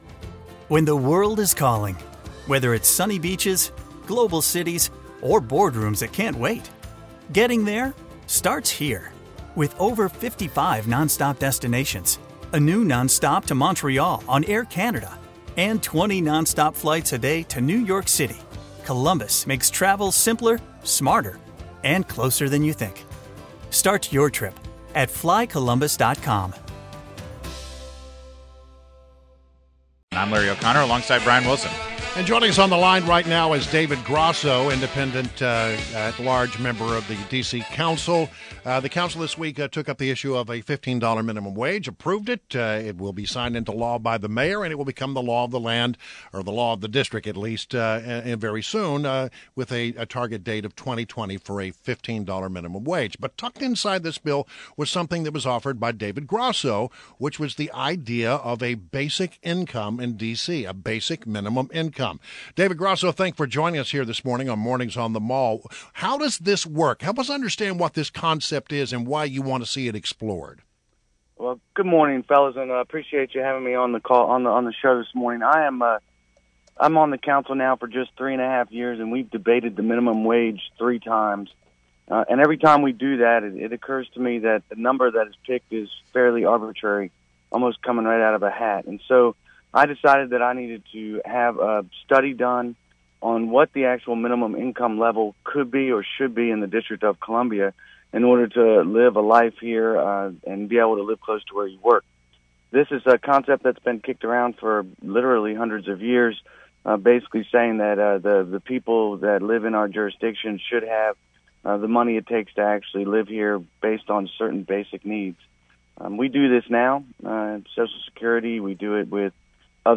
WMAL Interview - DC Councilmember DAVID GROSSO - 06.09.16